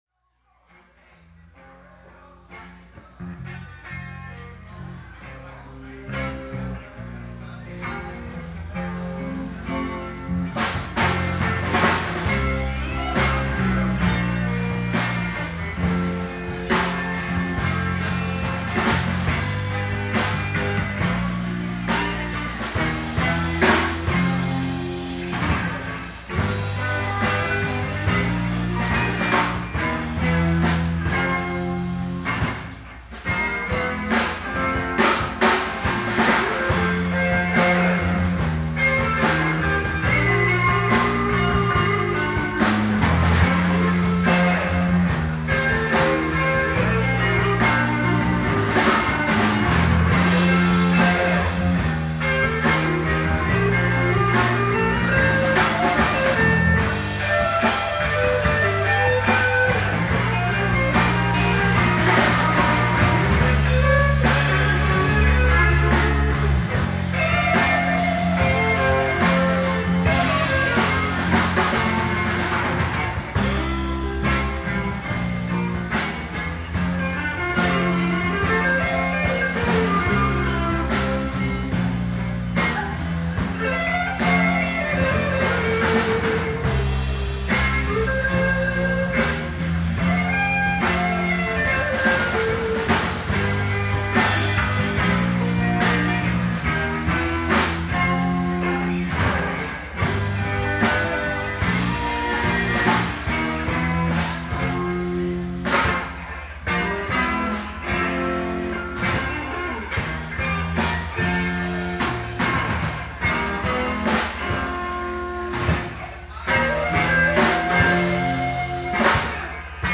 Final Farewell - Cafe L.A.
01 Wind Cries Mary--Instrumental Soundcheck